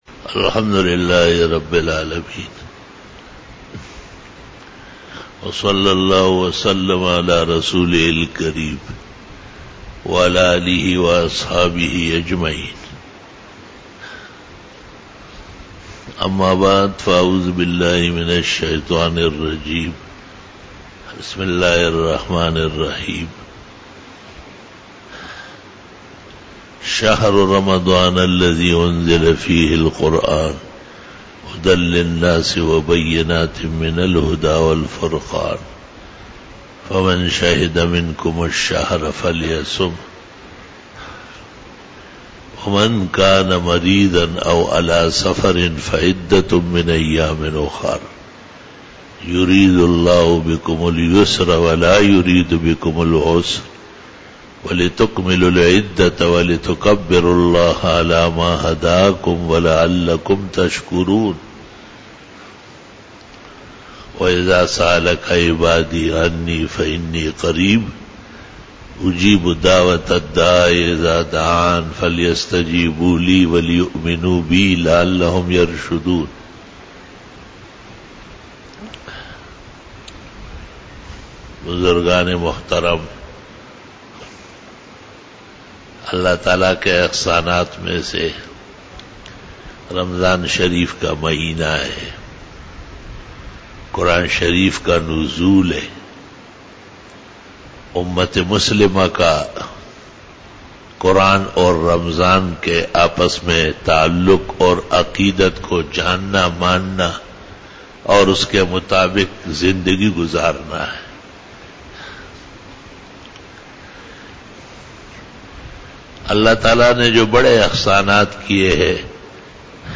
24 BAYAN E JUMA TUL MUBARAK (15 June 2018) (30 Ramadan 1439H)
Khitab-e-Jummah 2018